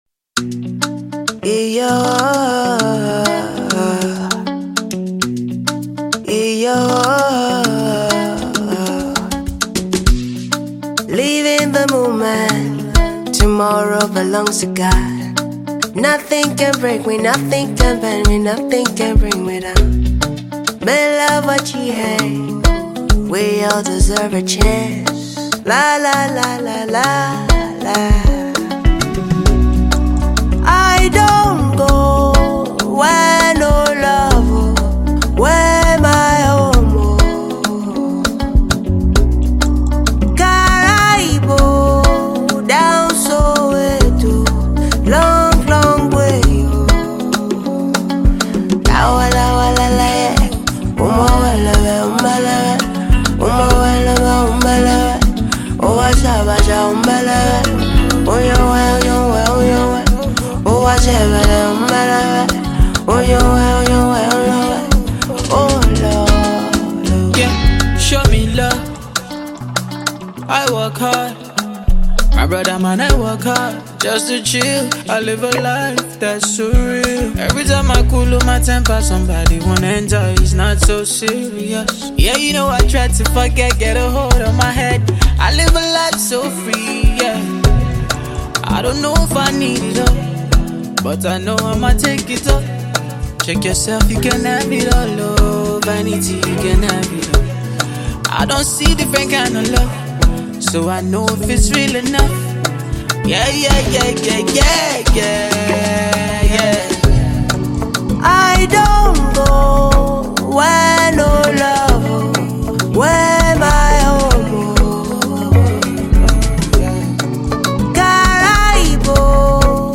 contagious Afrobeat song with a love theme